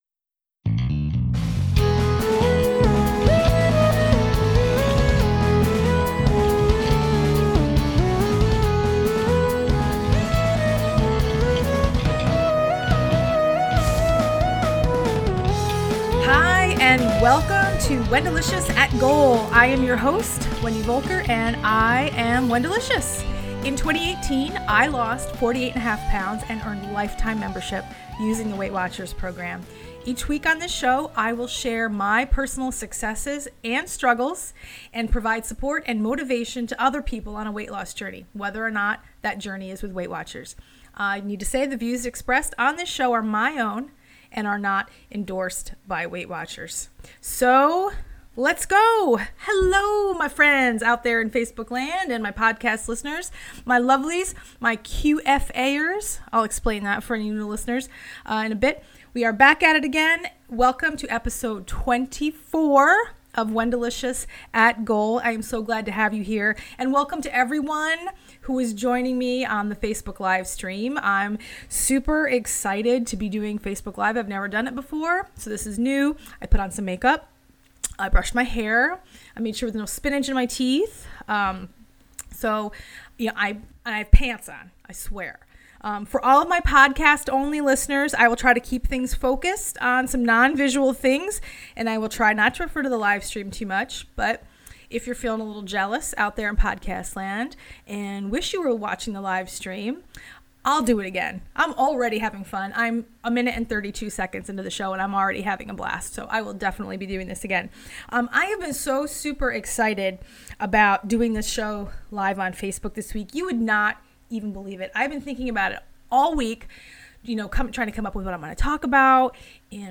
This episode was recorded as a Facebook LIVE video – so please pardon any references to my hair, spinach in my teeth, or the bags under my eyes. I will also be talking about success, things that make us feel successful, and things we can do right now to help us feel successful in the future.